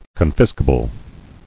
[con·fis·ca·ble]